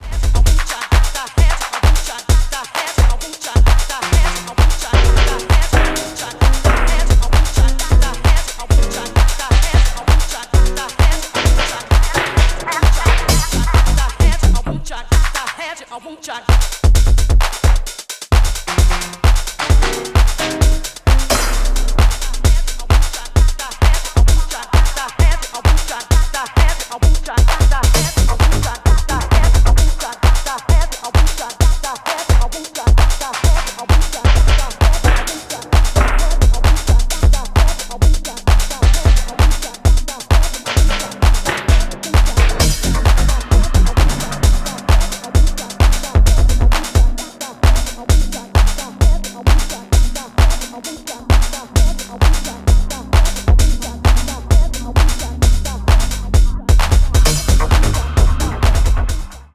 a raw house cut heavy on the 909s.